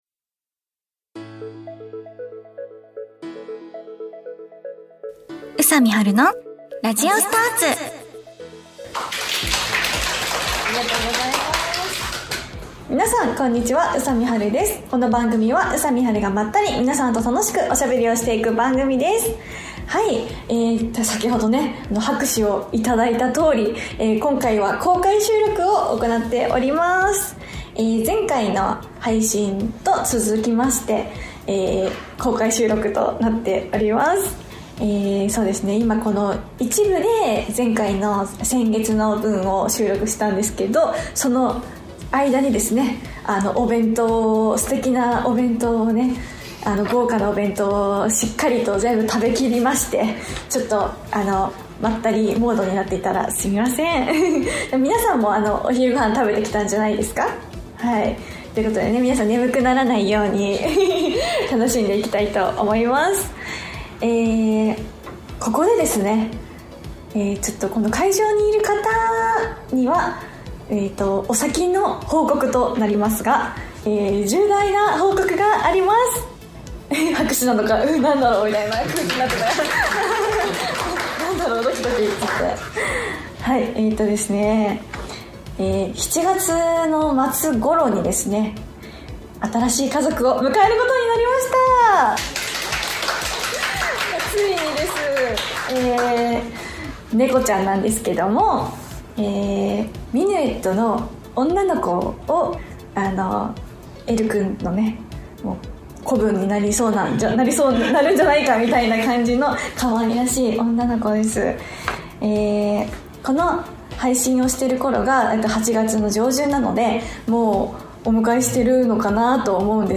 公開収録後半の部もあっという間に過ぎてしまいました♪